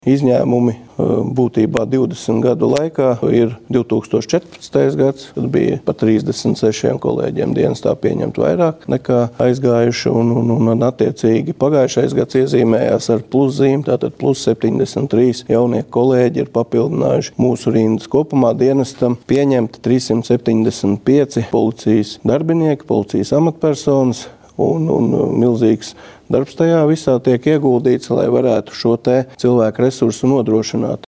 Valsts policijas priekšnieks Armands Ruks